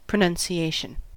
En-us-pronunciation.ogg.mp3